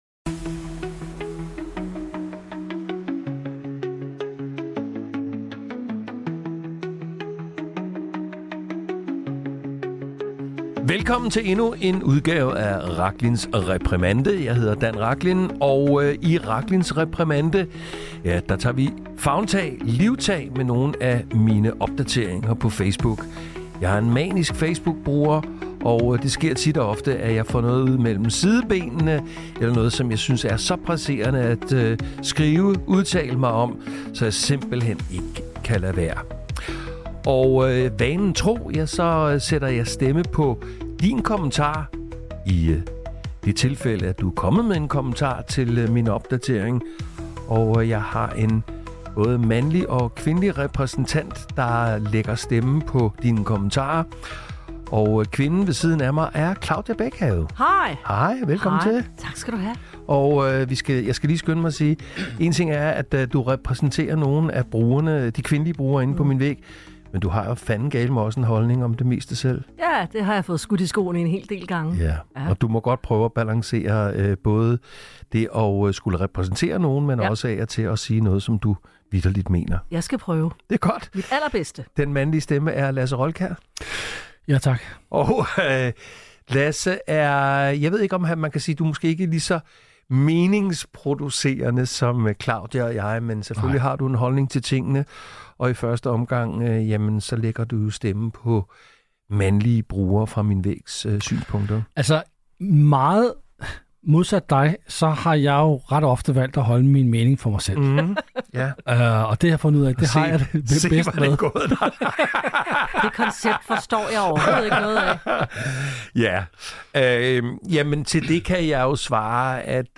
Medværter og stemmer til kommentarsporet